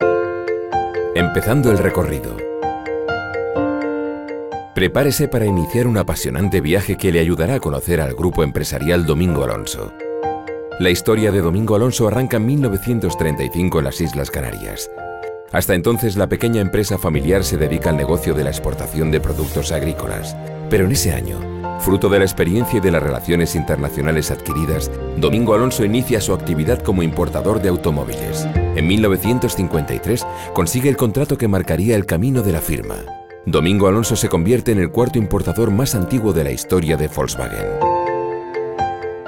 VOZ GRAVE, BAJO. MUY VERSATIL PARA TODO TIPO DE TRABAJOS: PUBLICITARIOS, CORPORATIVOS, E-LEARNING...
kastilisch
Sprechprobe: Industrie (Muttersprache):